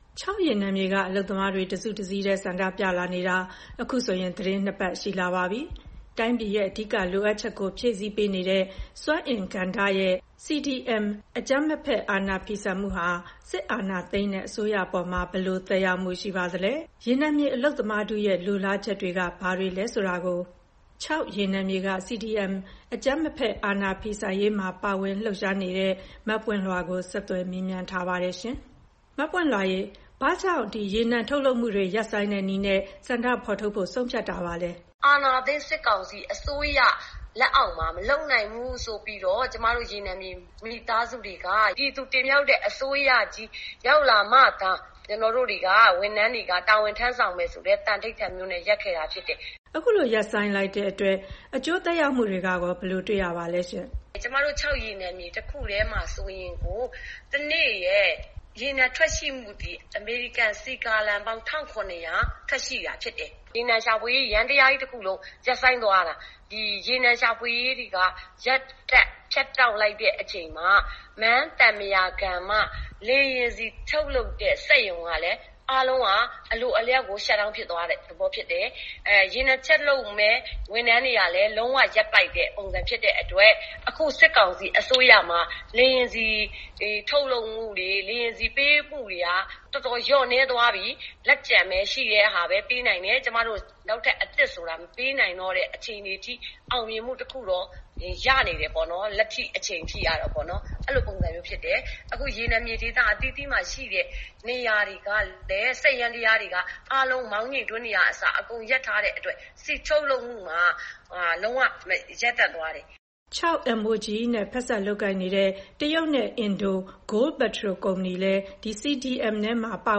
ချောက်ရေနံမြေဆန္ဒပြသူ တဦးနဲ့ ဆက်သွယ်မေးမြန်းချက်